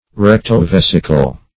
Search Result for " recto-vesical" : The Collaborative International Dictionary of English v.0.48: Recto-vesical \Rec`to-ves"i*cal\ (-v?s"?*kal), a. (Anat.) Of or pertaining to both the rectum and the bladder.
recto-vesical.mp3